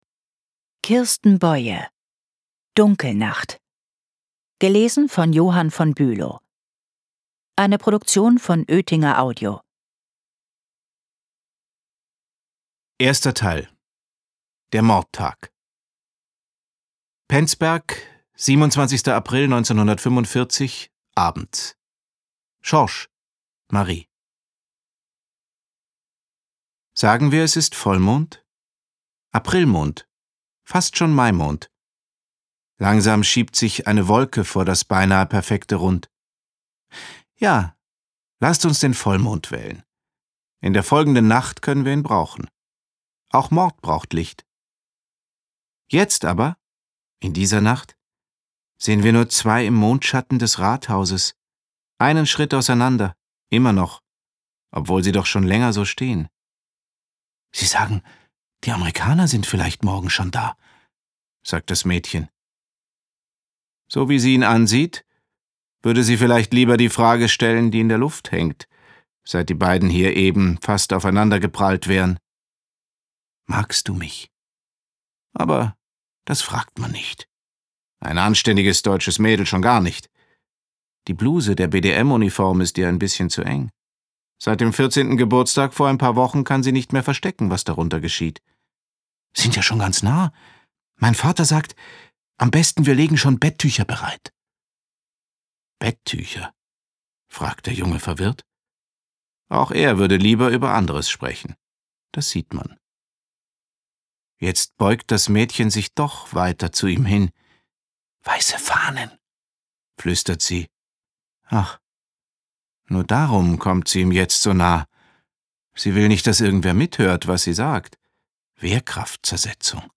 2 CDs - ungekürzte Lesung
gelesen von Johannes von Bülow